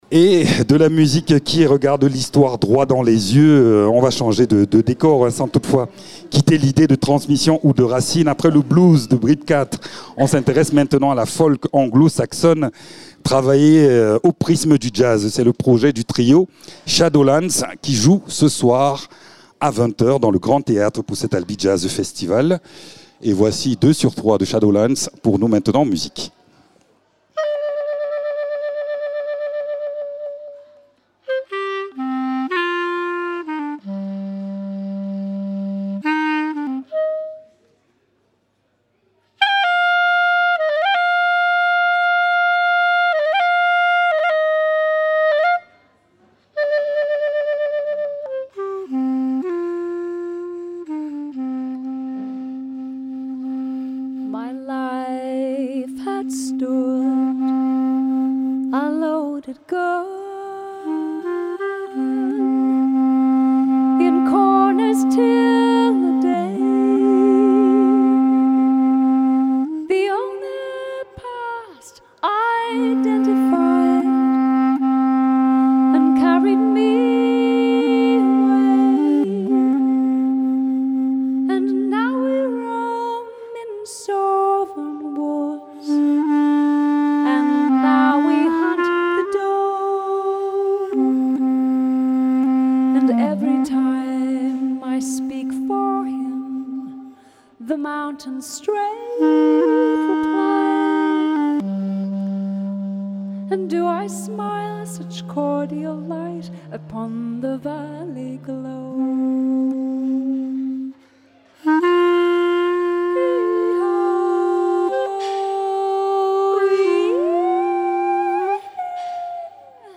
La tradition folk anglo-saxonne revisitée au prisme du jazz et de la musique improvisée. Une poésie qui nous fait flotter dans un univers enchanteur et spirituel.
à la voix
au saxophone ténor et à la clarinette